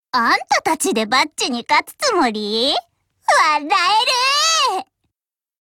Cv-10135_warcry.mp3 （MP3音频文件，总共长5.6秒，码率320 kbps，文件大小：219 KB）